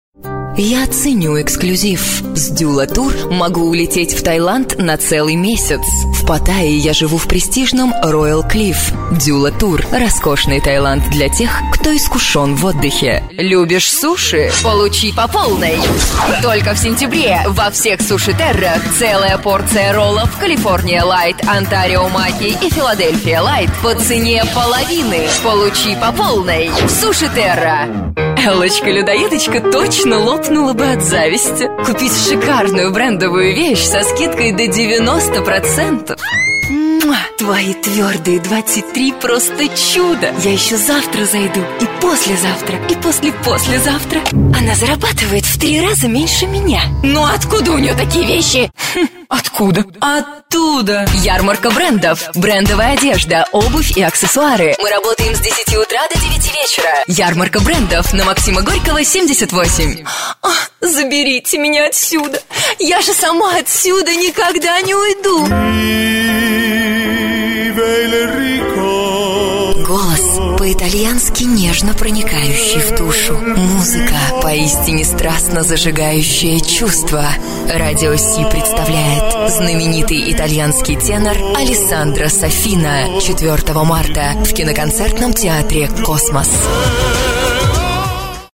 Роскошное меццо-сопрано, красивый, сильный, уверенный, презентабельный голос.